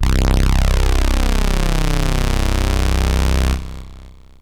SYNTH BASS-2 0010.wav